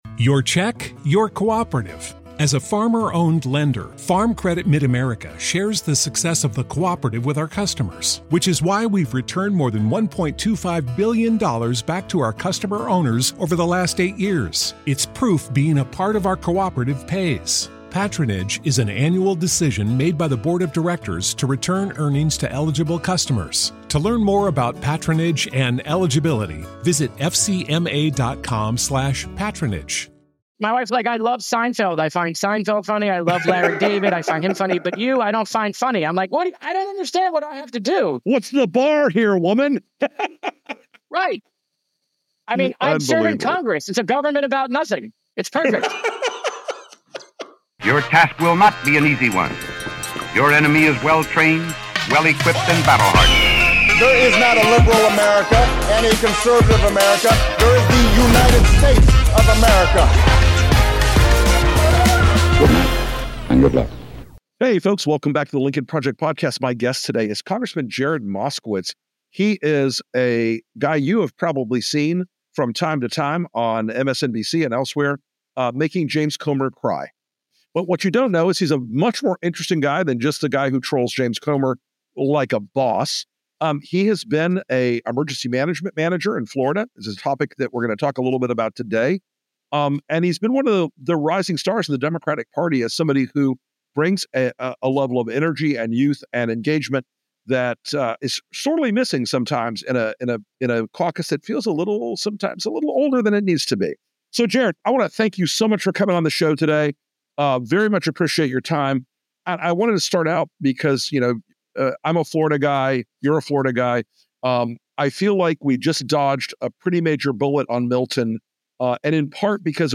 Rick Wilson sits down with Congressman Jared Moskowitz to unpack the performative politics dominating the Republican caucus, highlighting how spectacle often overshadows substance in today’s GOP, and how good it feels to make James Comer eat his words. They explore the impact of these theatrics on effective governance, especially during crises, and how misinformation fuels political grandstanding.